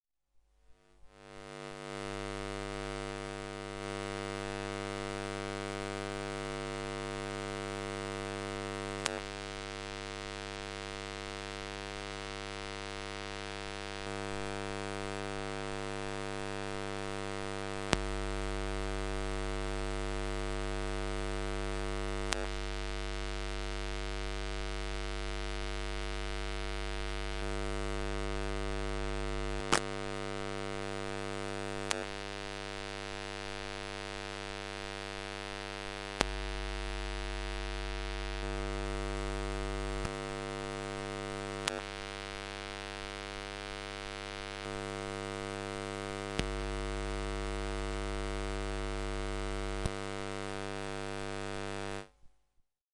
电磁场 " 双灯开关
描述：使用Zoom H1和电磁拾音器录制